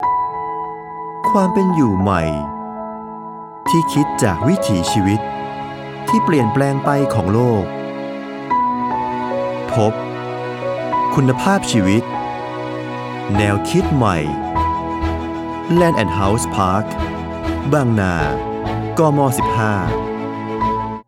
张力温度-电台主播